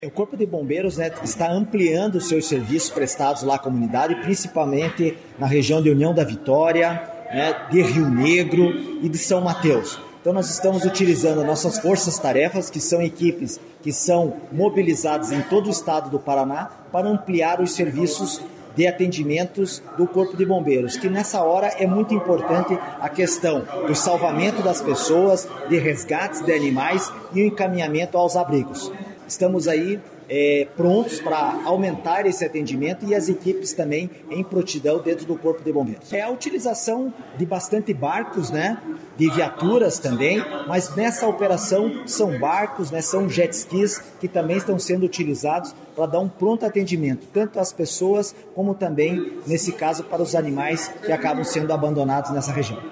Sonora do comandante do Corpo de Bombeiros Militar do Paraná, coronel Vasco Figueiredo, sobre o envio de efetivo para ajuda às vitimas das chuvas no Paraná